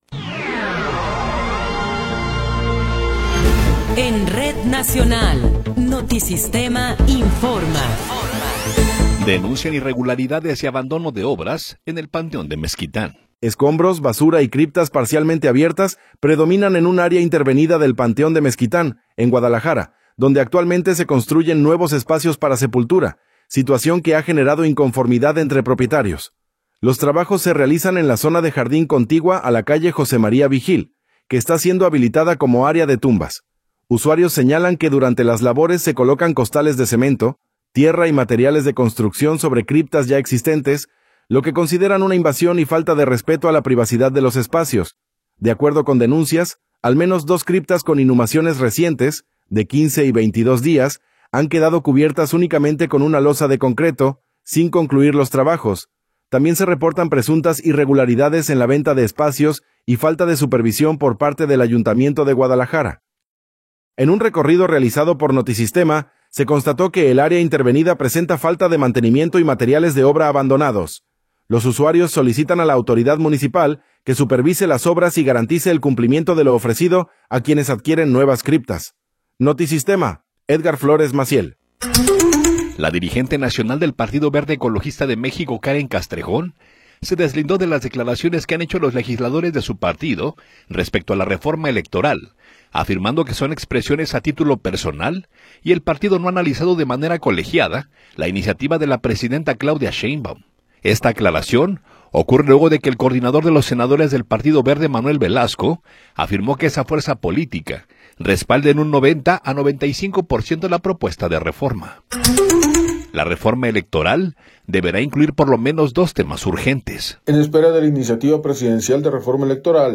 Noticiero 16 hrs. – 3 de Marzo de 2026
Resumen informativo Notisistema, la mejor y más completa información cada hora en la hora.